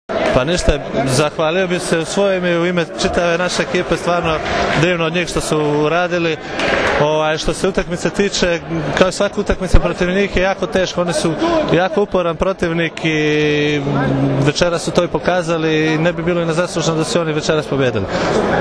IZJAVA MILOŠA NIKIĆA